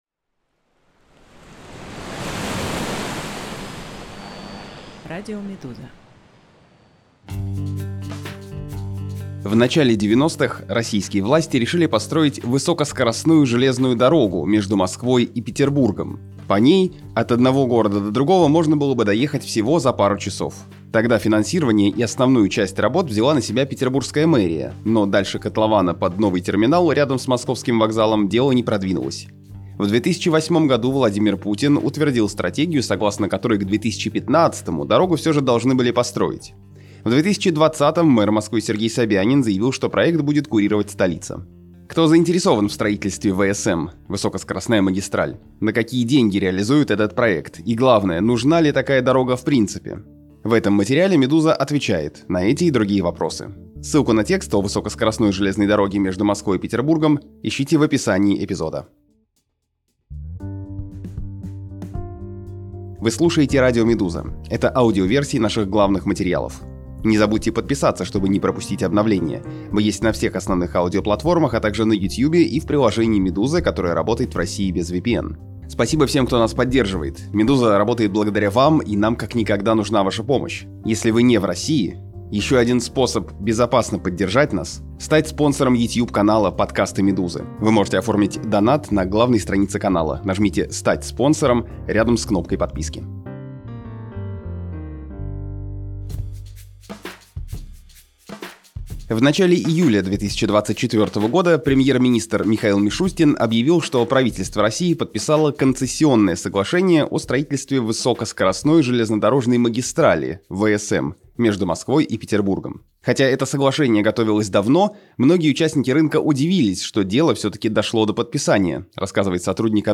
Аудиоверсию текста слушайте на «Радио Медуза»